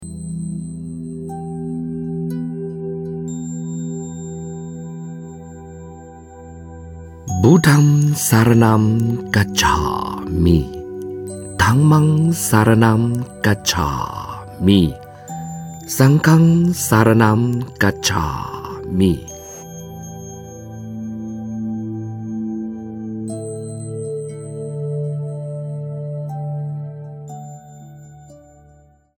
巴利语三皈依颂.mp3.ogg